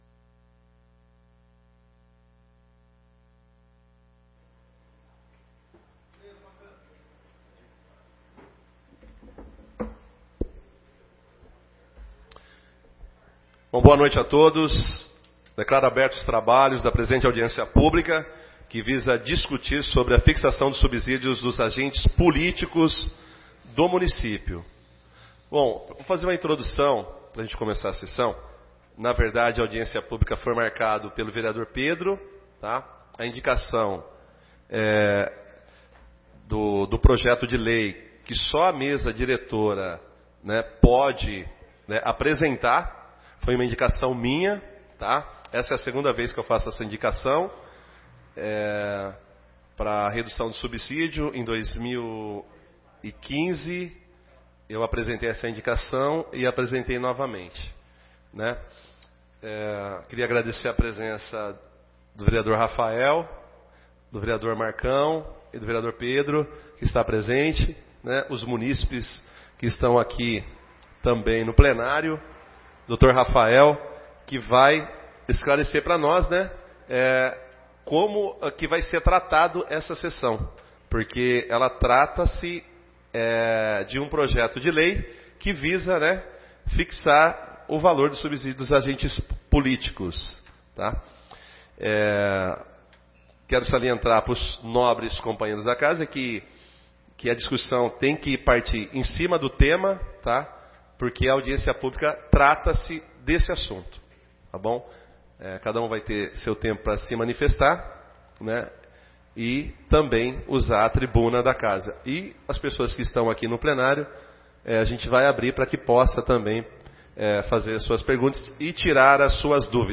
15/06/2018 - Audiência Pública para discutir sobre a fixação dos subsídios dos agentes políticos